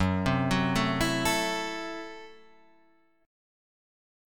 F#m7b5 chord